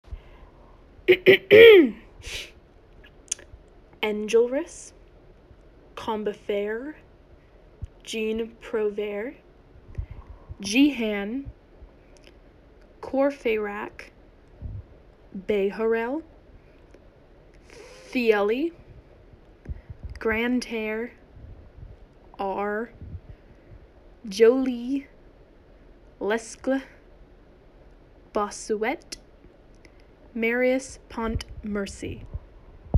My pronunciations of Les Amis’ names 🙂 hope it helps 🙂